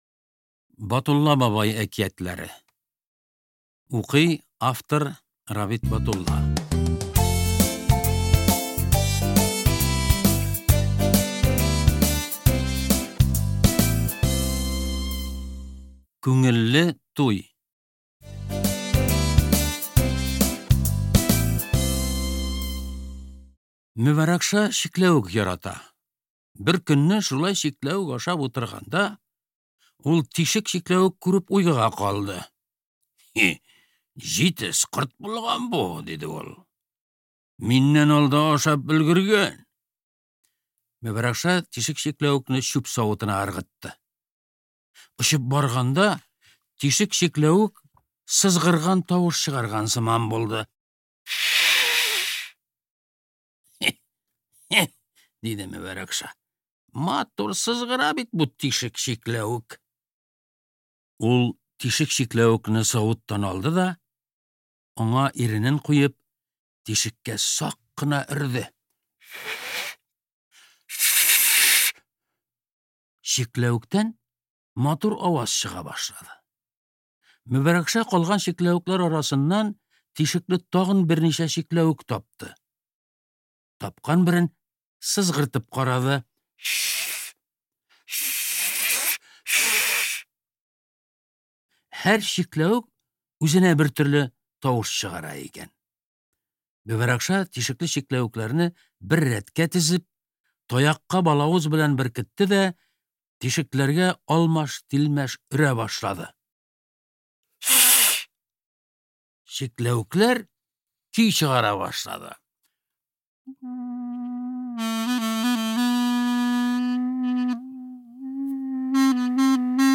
Аудиокнига Шаян бака | Библиотека аудиокниг